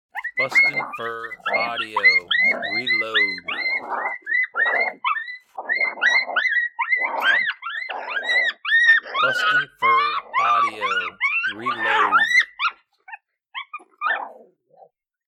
Adult male and female Coyotes aggressively fighting over food!
• Product Code: pups and fights